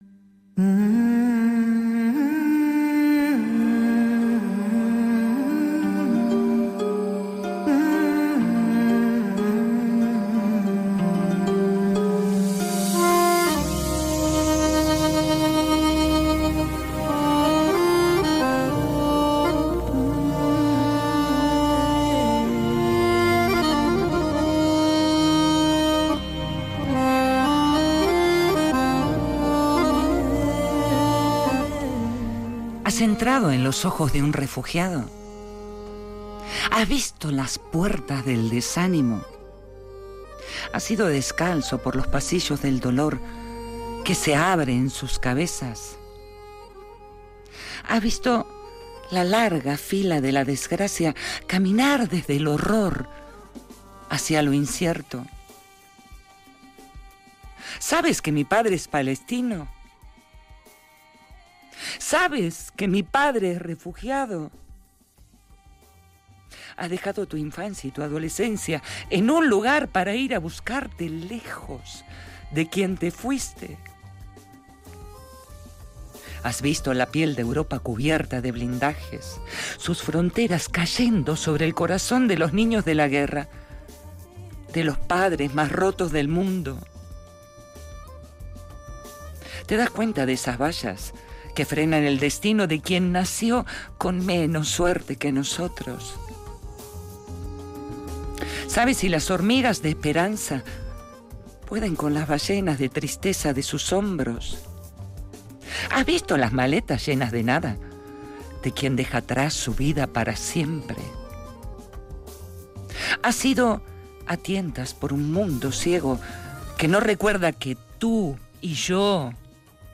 Lectura del poema Refugiados, un poema de Marwan en el programa La Fiaca